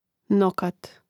nȍkat nokat